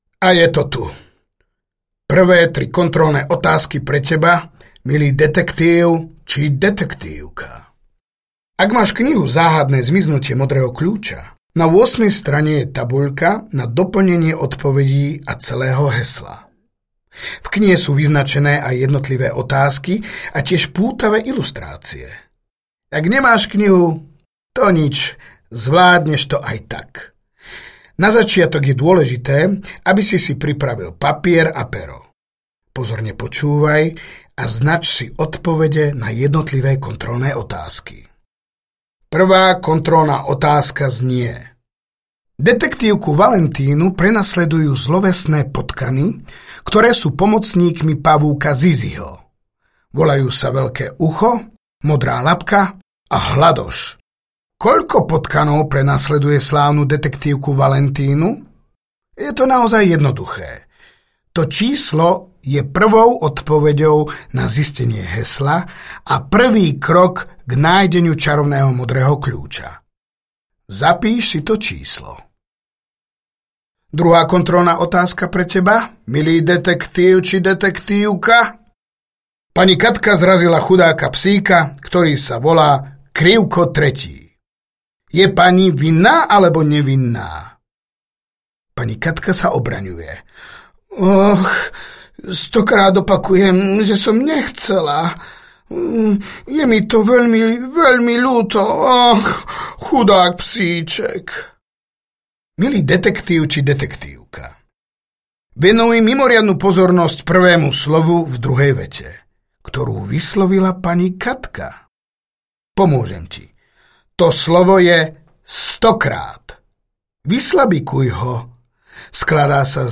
Ukázka z knihy
Knihu Záhadné zmiznutie modrého kľúča bravúrne nahovoril herec Marián Labuda mladší.